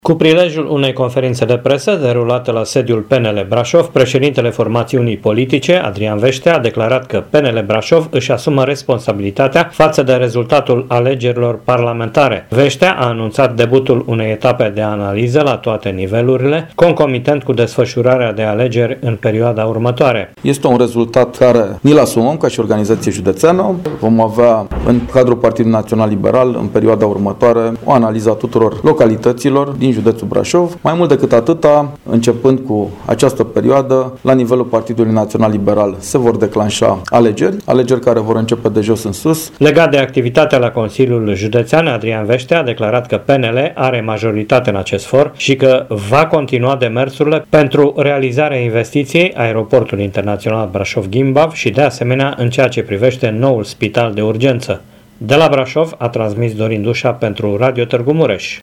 Cu prilejul unei conferințe de presă, derulată la sediul PNL Brașov, președintele formațiunii politice, Adrian Veștea a declarat că PNL Brașov își asumă responsabilitatea față de rezultatul alegerilor parlamentare.